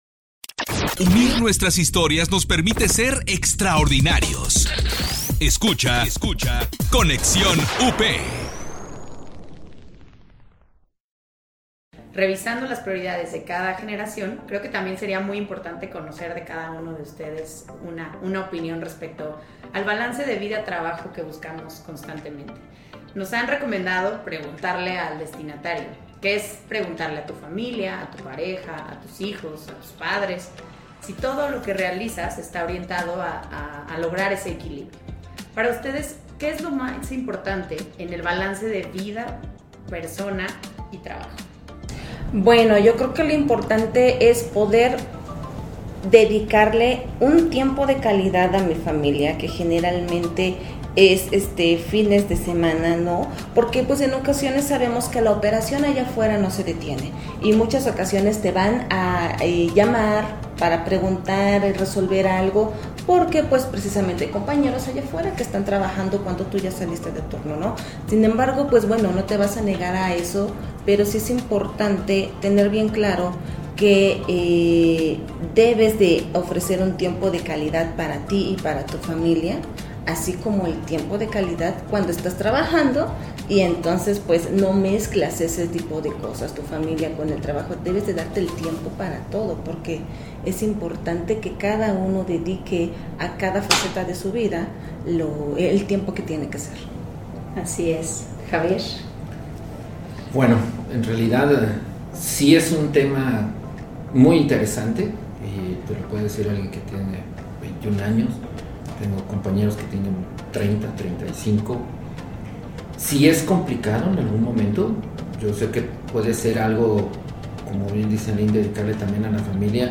En este episodio reunimos a tres invitados representando a varias generaciones que pertenecen a la Gran Familia Pilgrim's.